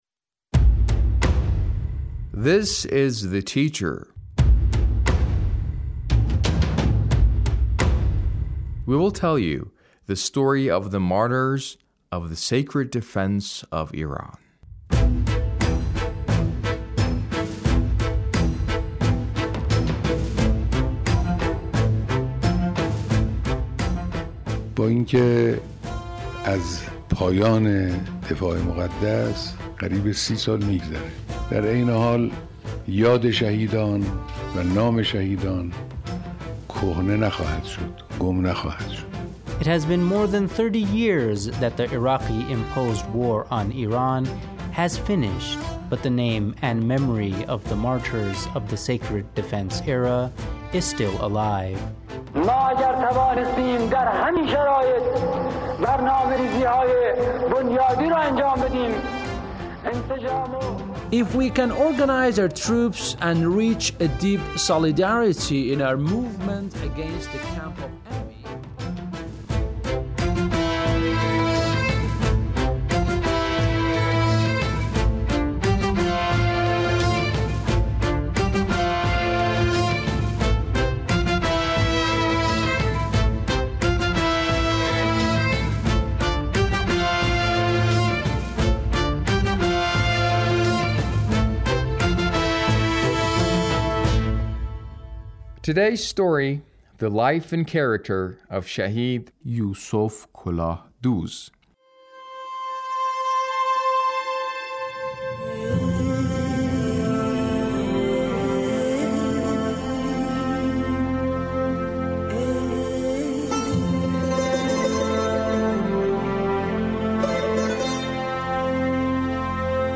A radio documentary on the life of Shahid Yusof Kolahdooz- Part 3